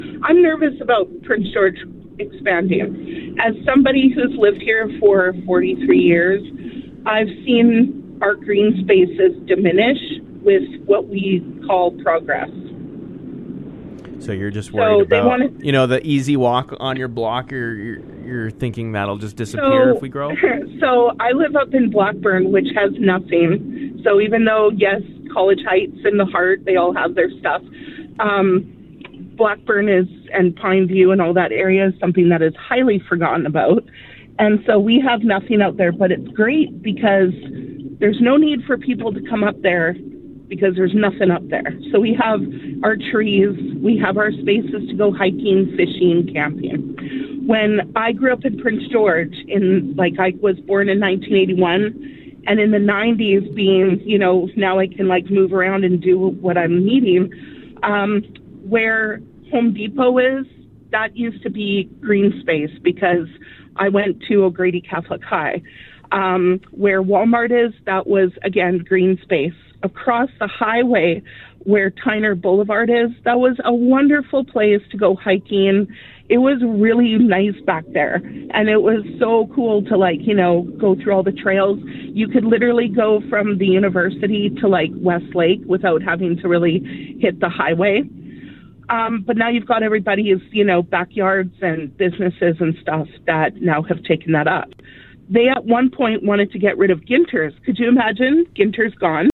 A call